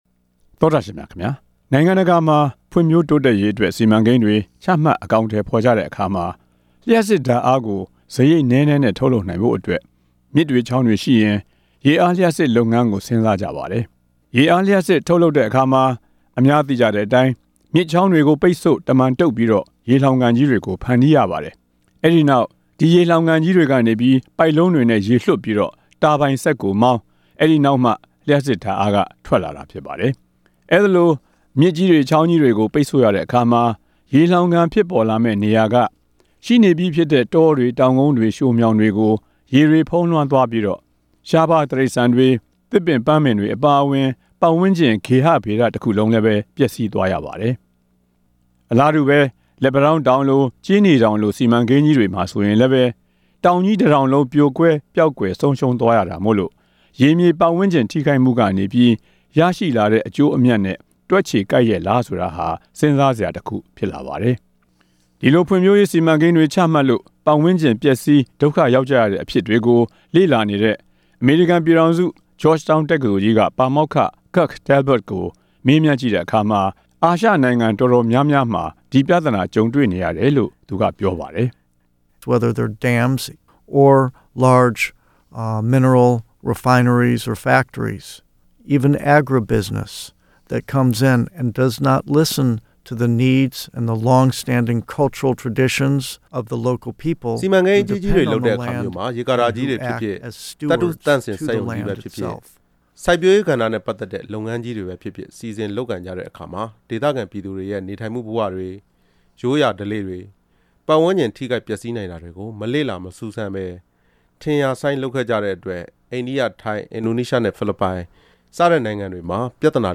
တွေ့ဆုံ မေးမြန်းပြီး